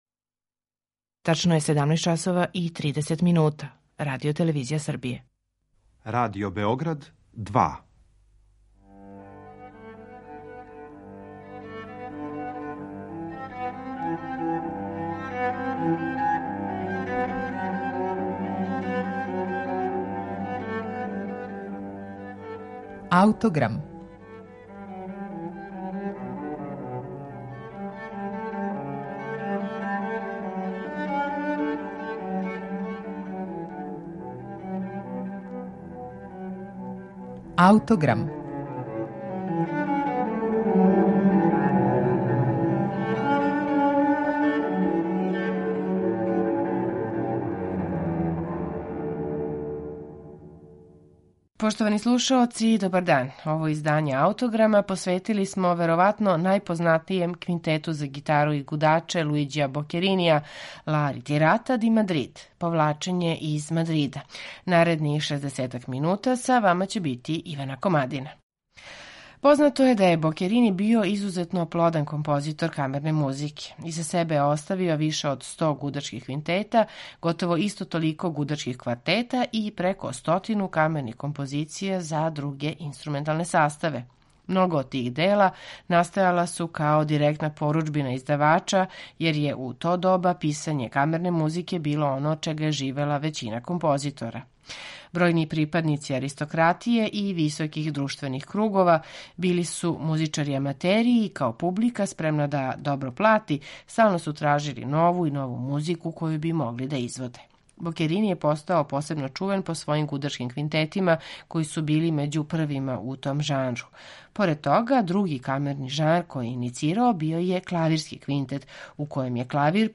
Квинтете за гитару и гудачки квартет